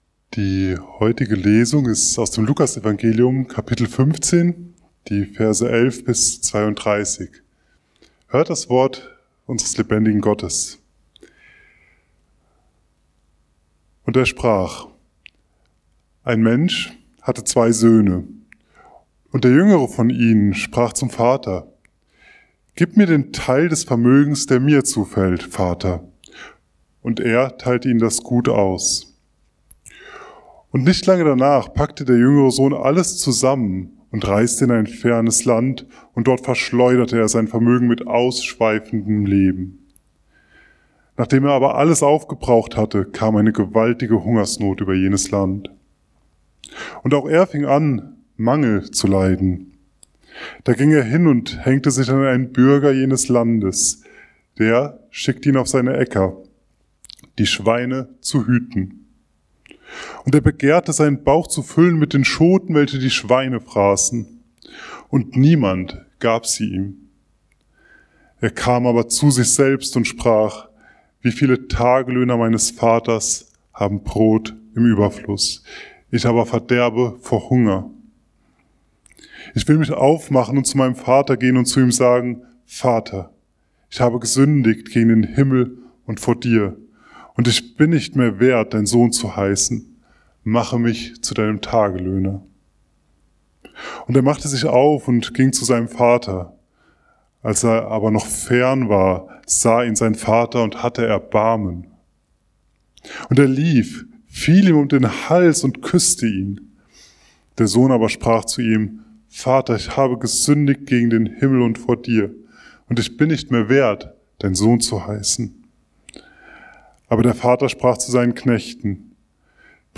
Eine Einladung zur Heimkehr / An Invitation to Come Home ~ Mittwochsgottesdienst Podcast